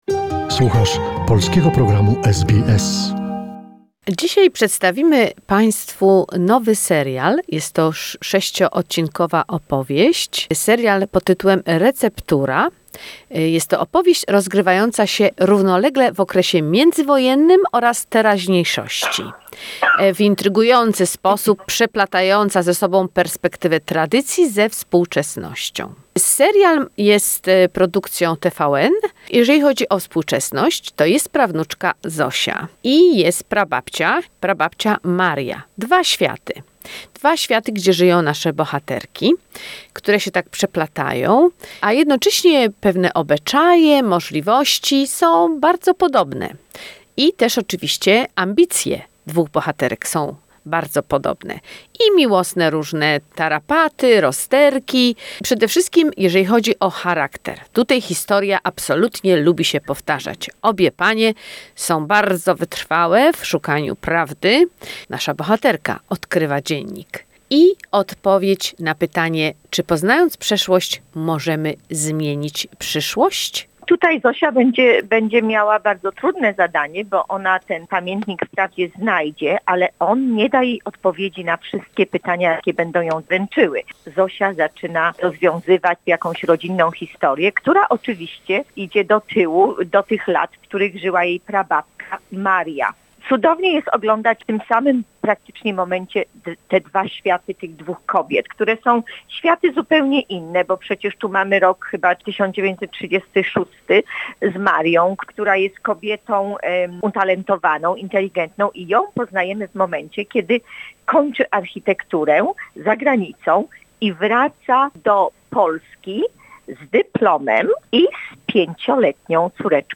Film Review "Receptura"